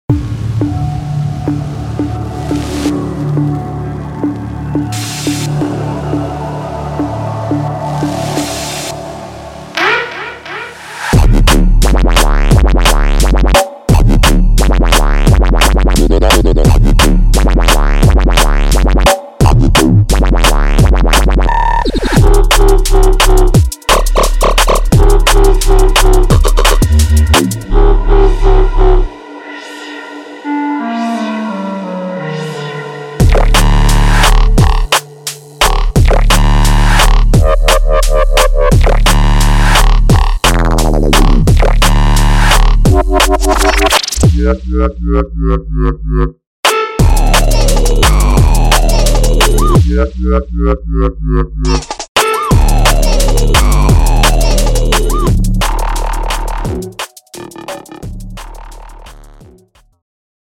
Drum And Bass
WOBBBZZZ!